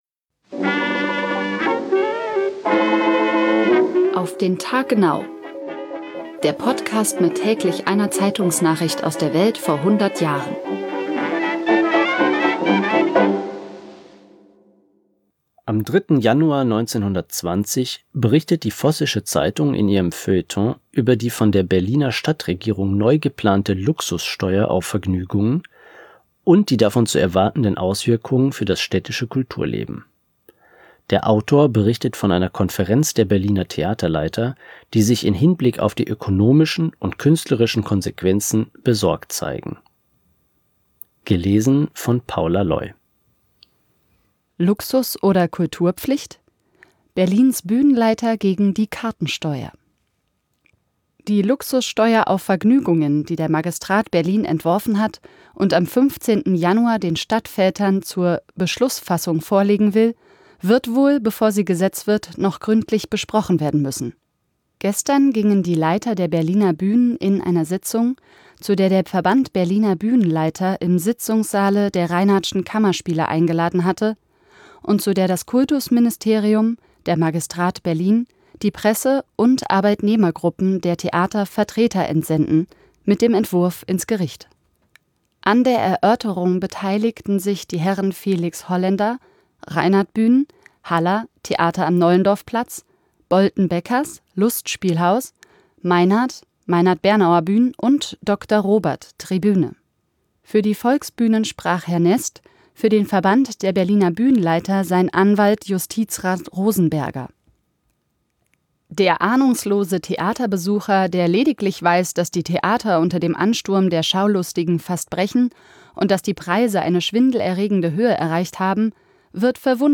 Gelesen